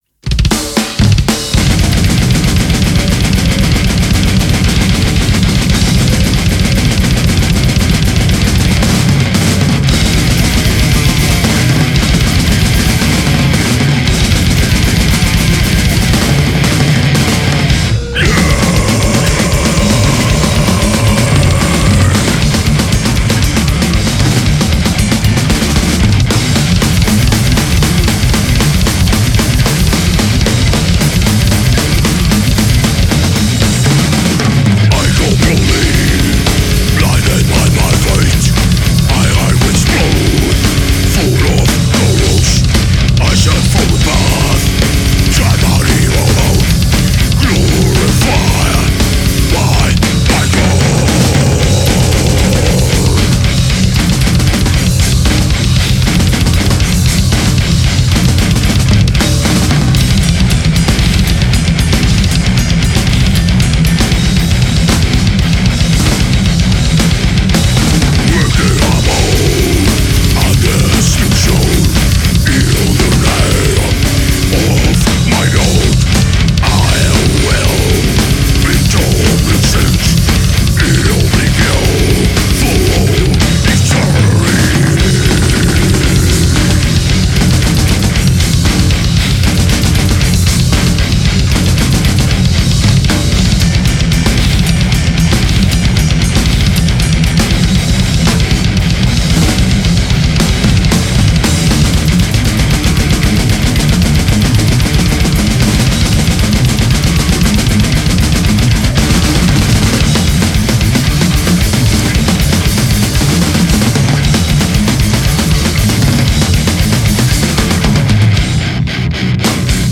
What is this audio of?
Genre: deathmetal.